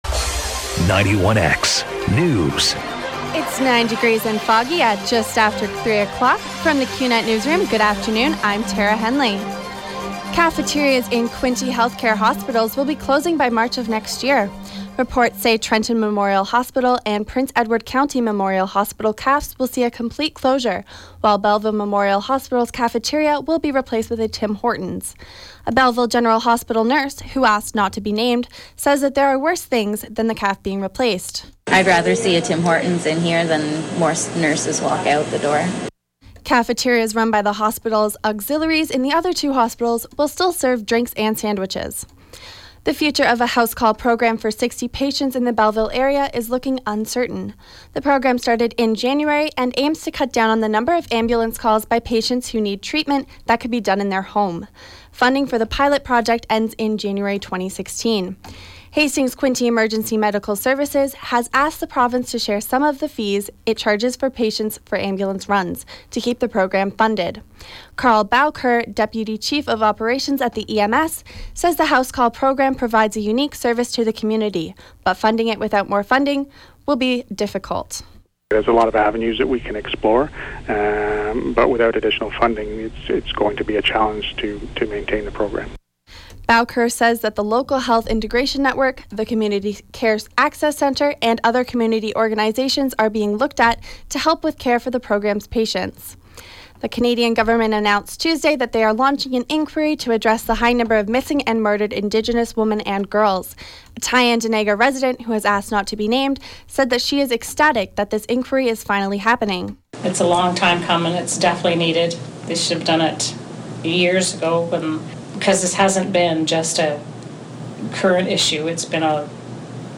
91X newscast – Thursday, Dec. 10, 2015 – 3 p.m.